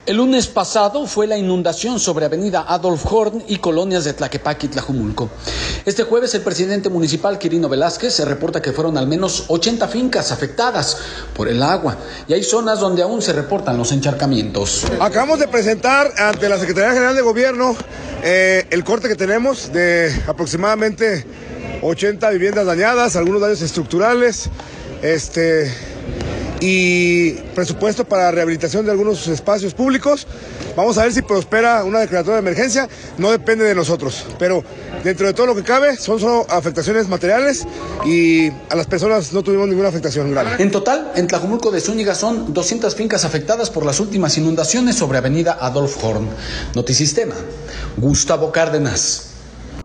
El lunes pasado fue la inundación sobre avenida Adolf Horn y colonias de Tlaquepaque y Tlajomulco. Este jueves, el presidente municipal Quirino Velázquez reporta que fueron al menos 80 fincas afectadas por el agua y hay zonas donde aún se reportan los encharcamientos.